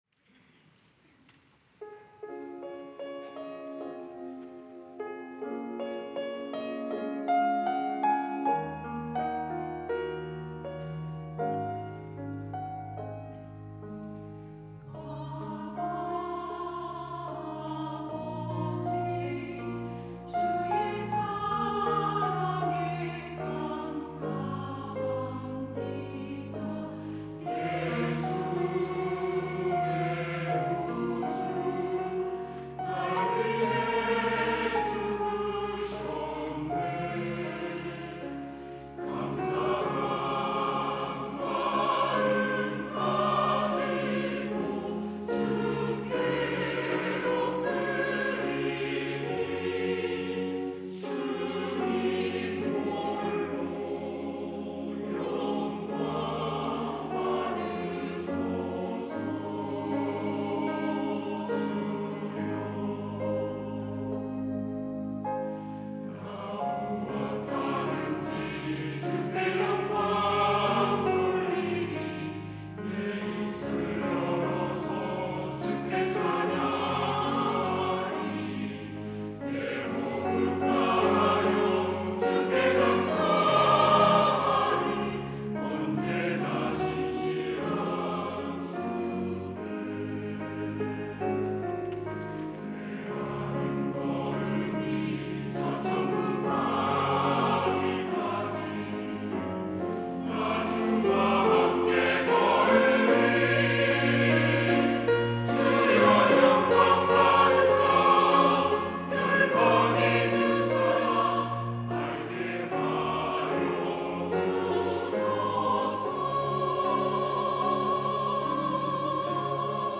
시온(주일1부) - 나 무엇하든지 주께 영광 돌리리
찬양대